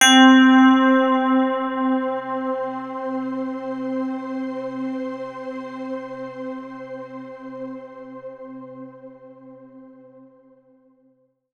Here is a sample from a fm piano/pad layer.